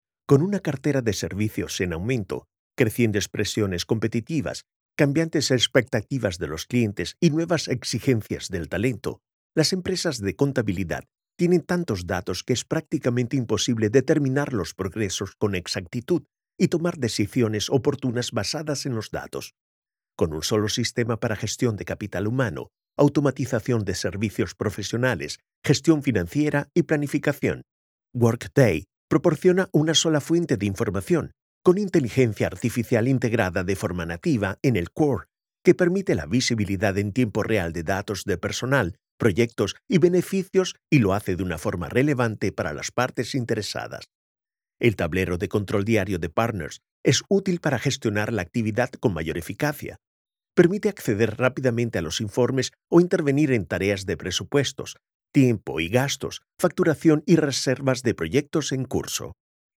Commercieel, Jong, Stoer, Veelzijdig, Zakelijk
Corporate